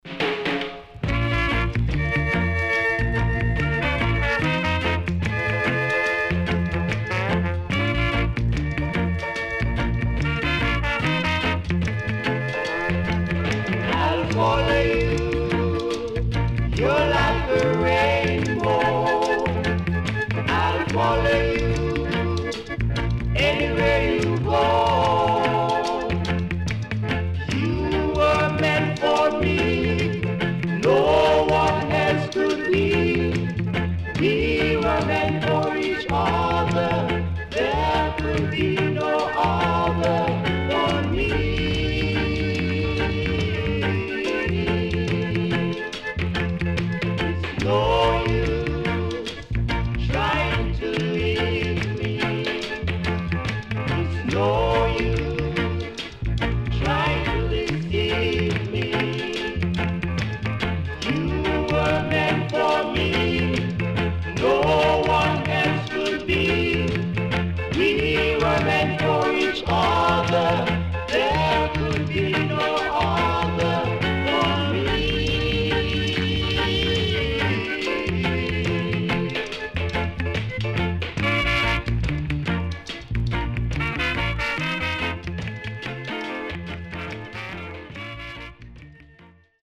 HOME > REGGAE / ROOTS  >  EARLY REGGAE  >  定番70’s
CONDITION SIDE A:VG(OK)〜VG+
SIDE A:うすいこまかい傷ありますがノイズあまり目立ちません。